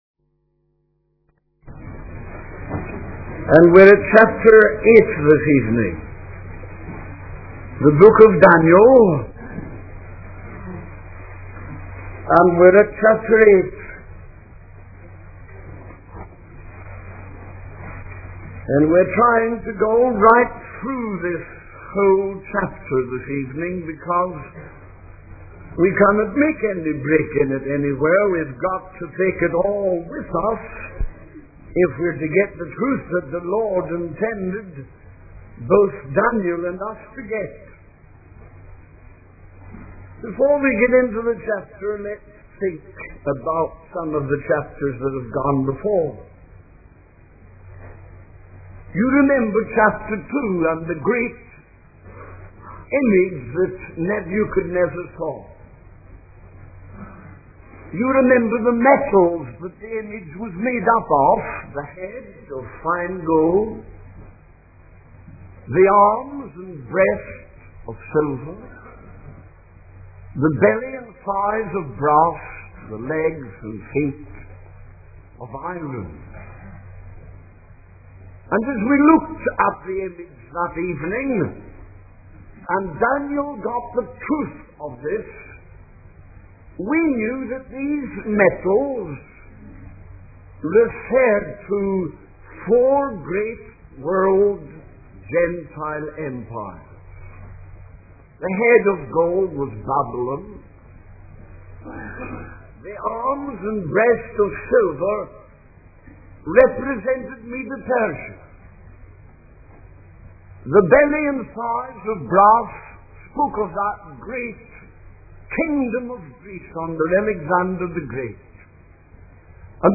In this sermon, the speaker discusses the visions that Daniel had regarding the four great world empires.